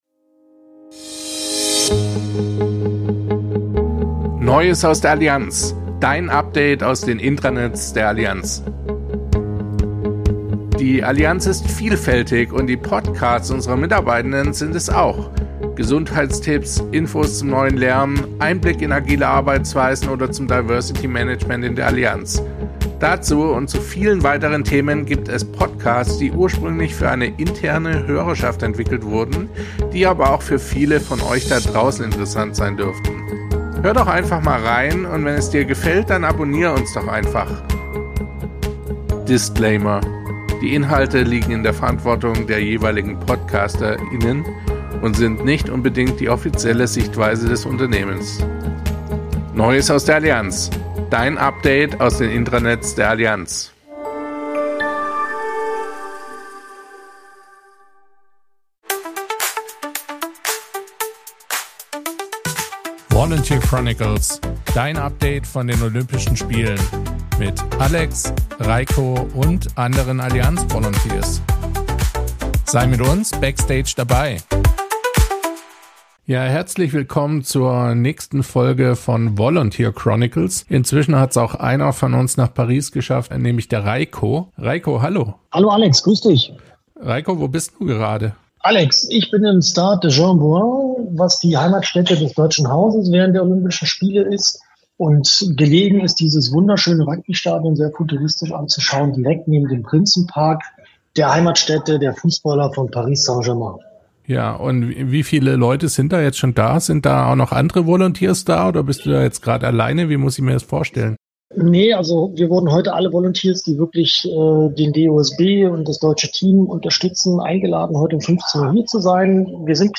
geschafft. Er berichtet direkt aus dem deutschen Haus, was er dort